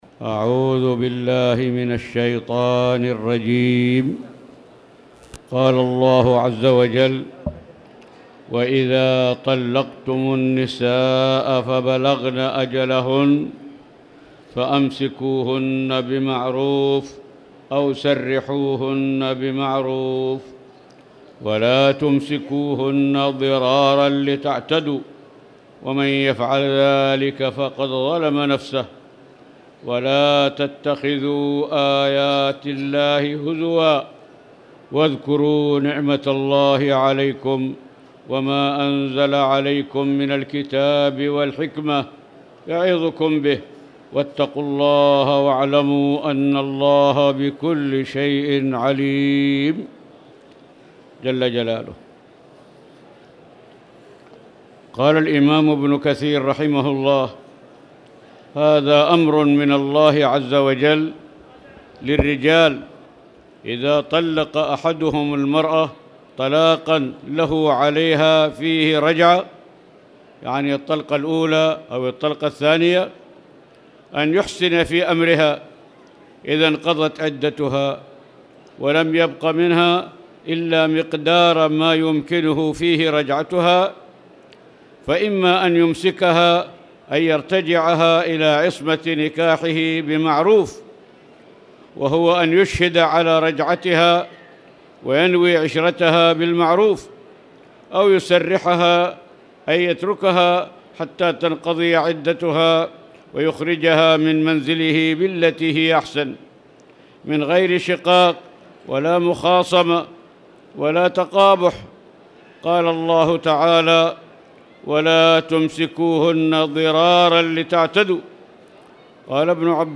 تاريخ النشر ٦ جمادى الأولى ١٤٣٨ هـ المكان: المسجد الحرام الشيخ